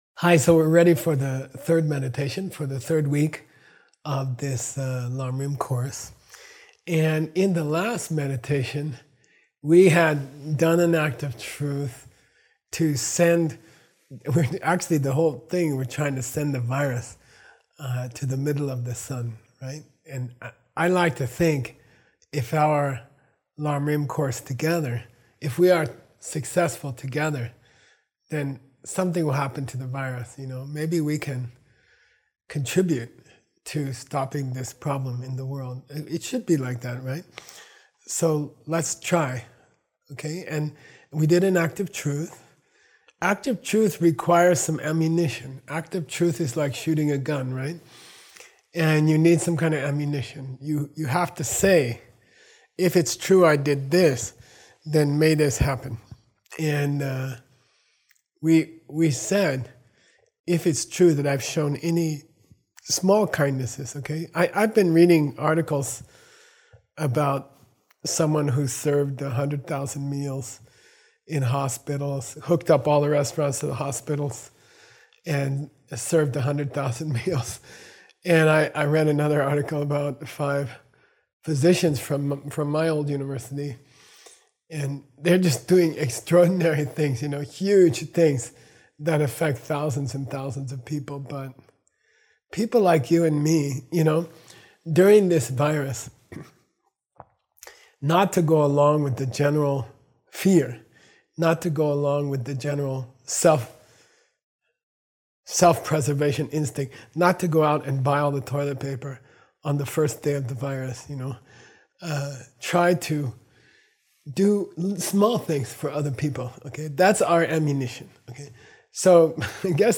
Meditation Audio